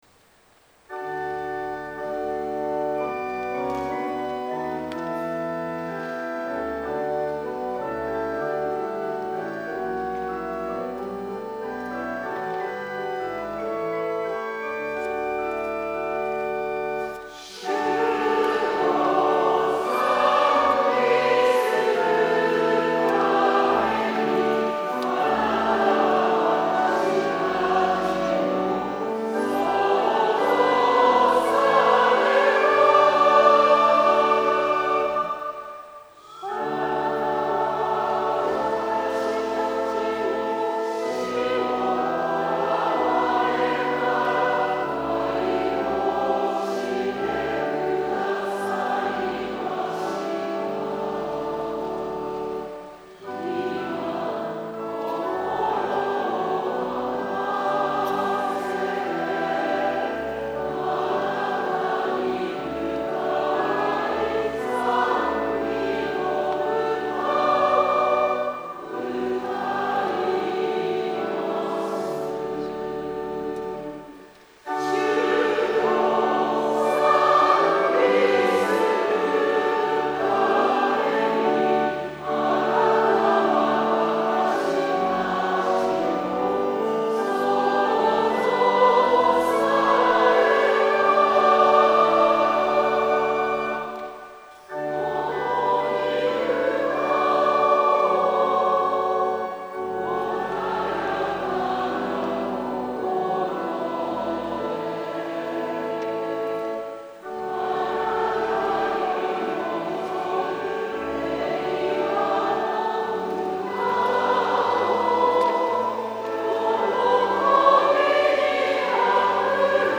混声四部合唱+器楽 Four-part mixed chorus with Instruments
1.0.2 D Choir(S,A,T,B)
第36回教会音楽祭にて
於 ICUチャペル